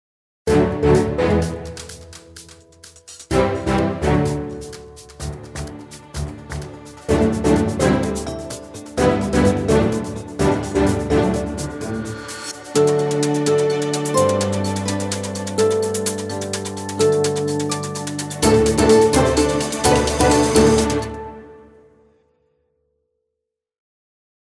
Upbeat track for reality TV and transitions & stingers.